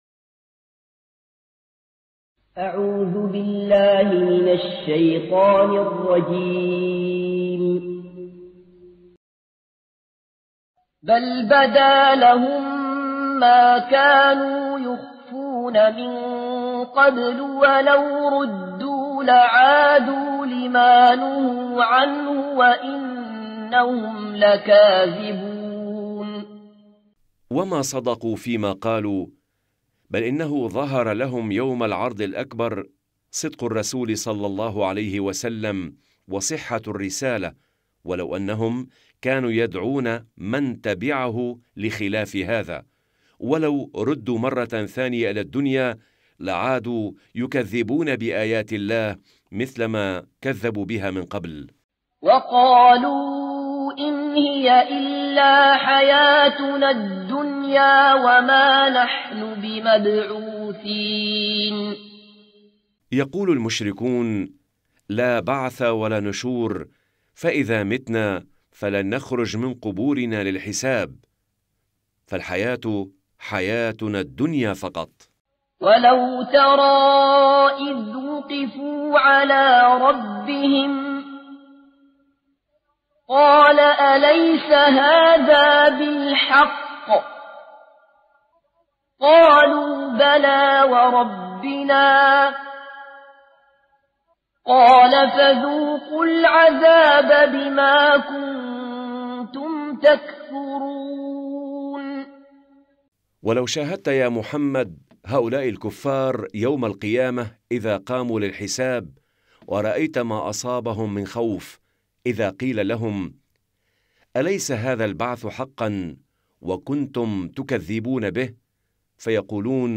مجلدات التفسير تلاوة تعليمية للقرآن الكريم مع التفسير الميسر